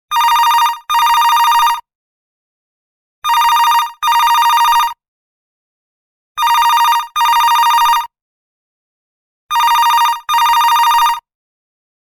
Phone Ring Sound Effect
Telephone-ring-sound-effect.mp3